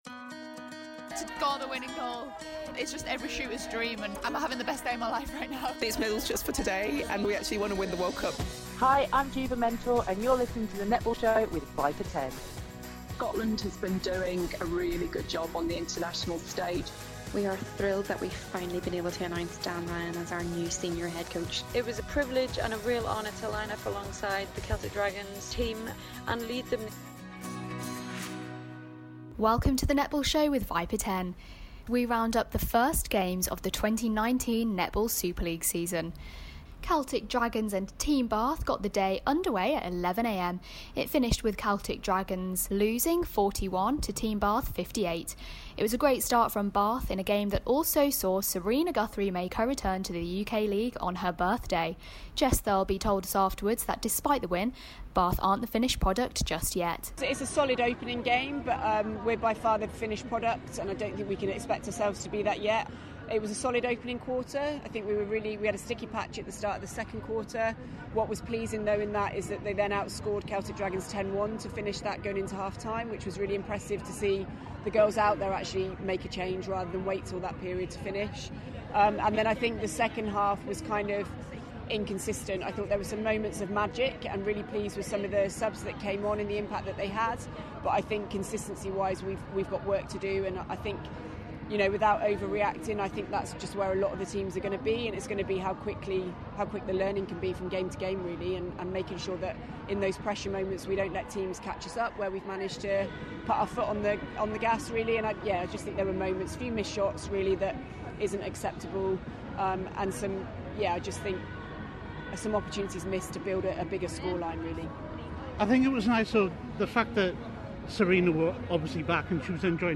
The Netball Super League 2019 gets underway in Birmingham and the UK netball podcast, The Netball Show with Viper 10 brings you post match reaction from coaches and players as we hear from all the teams that took part in the day of netball.